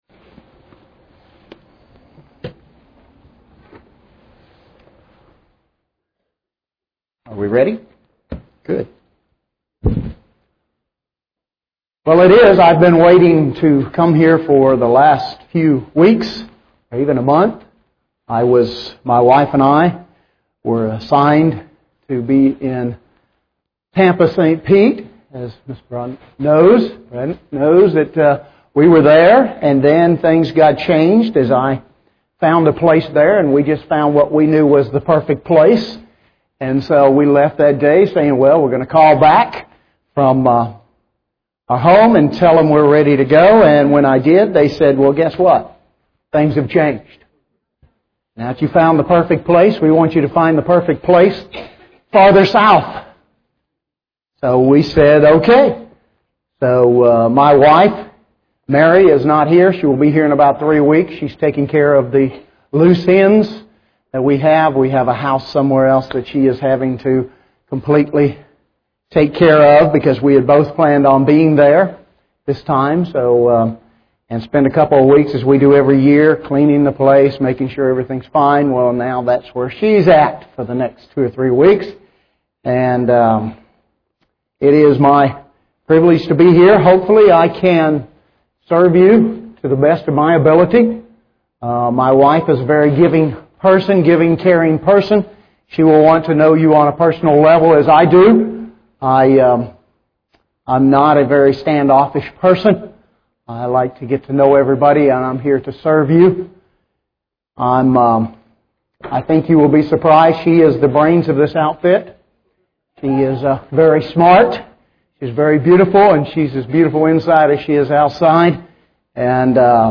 UCG Sermon Transcript This transcript was generated by AI and may contain errors.